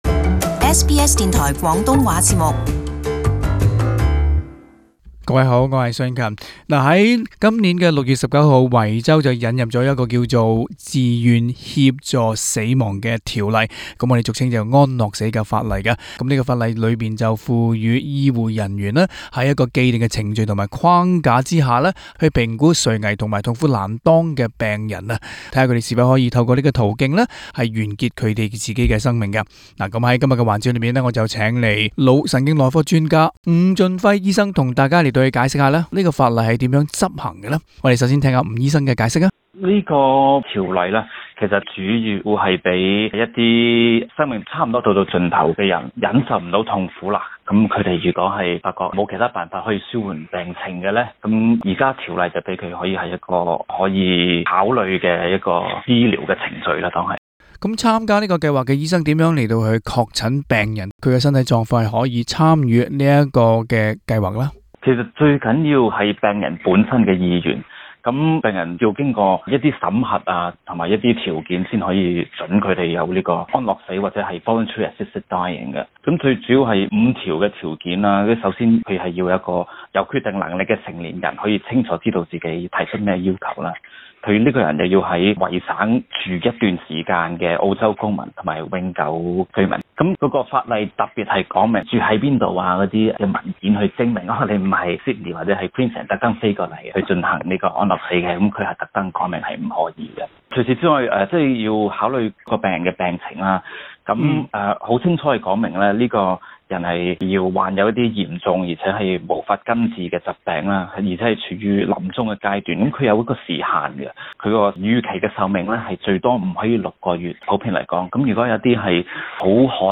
【社區專訪】維州安樂死法例 如何協助葯石無靈的病人